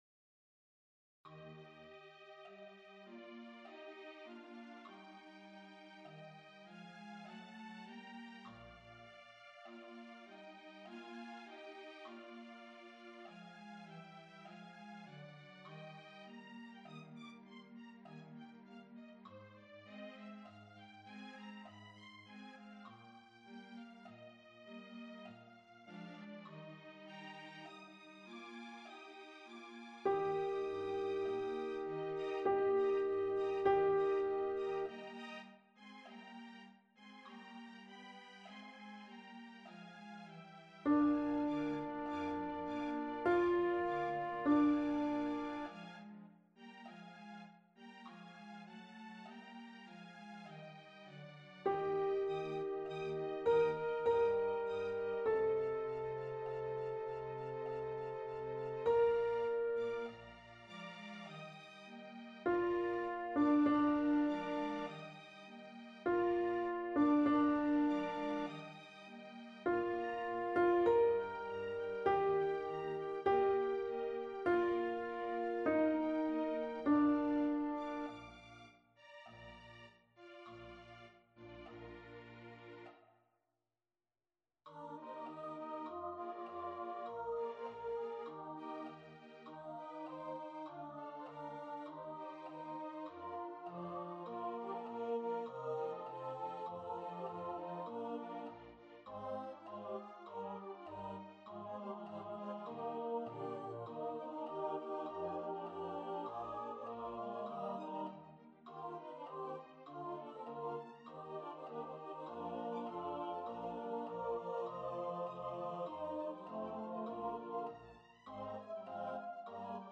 4-Gratias-AY-F2021-Alto.mp3